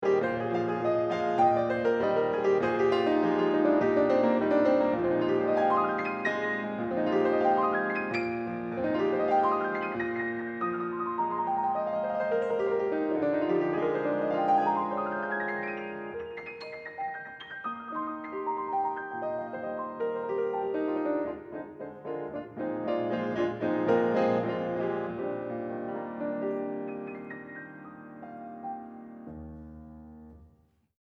Solo Piano pieces
Original music for acoustic solo piano
Solo piano